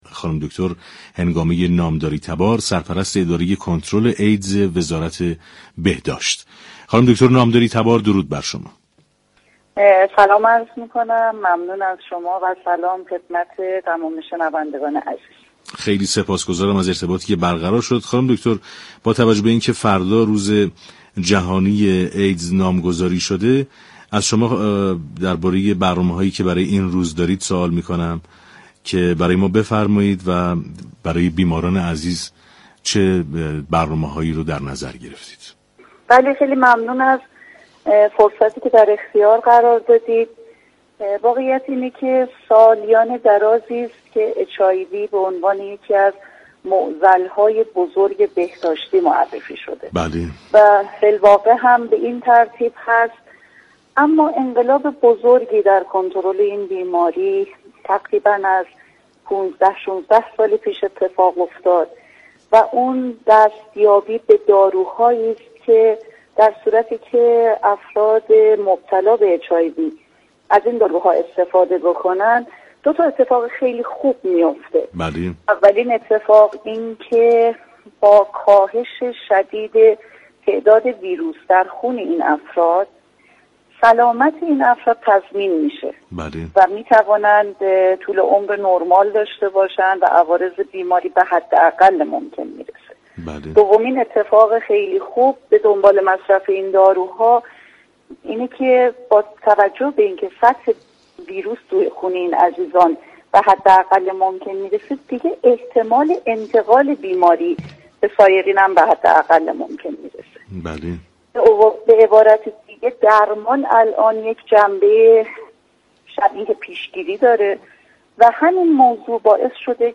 به گزارش پایگاه اطلاع رسانی رادیو تهران؛ هنگامه نامداری تبار سرپرست اداره كنترل ایدز وزارت بهداشت، درمان و آموزش پزشكی در گفت و گو با "تهران ما سلامت" رادیو تهران گفت: سالیان درازی است كه بیماری (HIV) اچ آی وی یكی از معضل‌های بزرگ بهداشتی معرفی شده است اما از 16 سال پیش تا كنون انقلاب بزرگی در كنترل این بیماری صورت گرفته است و آن هم دستیابی به داروهایی است كه مبتلایان با استفاده از این داروها می‌توانند نخست طول عمر بیشتری داشته باشند و با كمترین عوارض مواجه شوند و دوم به دلیل كاهش این ویروس در خون امكان انتقال این بیماری به سایرین نیز به حداقل می‌رسد.